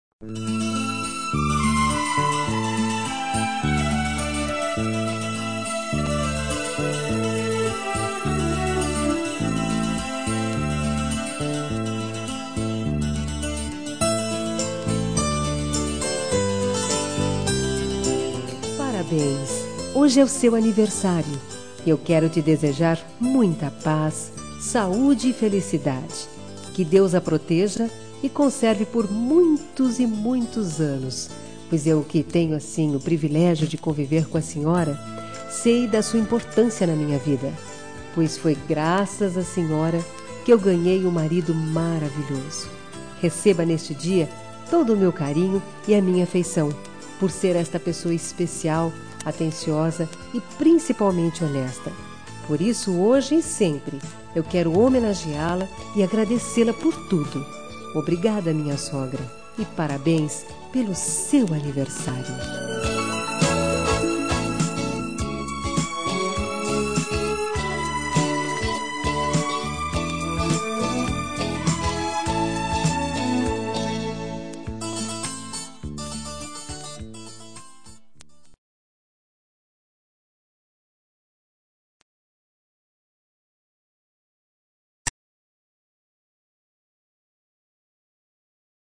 Telemensagem de Aniversário de Sogra – Voz feminina – Cód: 1965